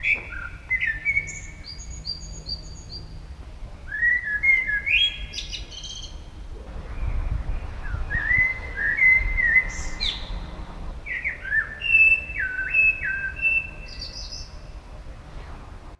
老鹰
描述：这是一只鹰。
声道立体声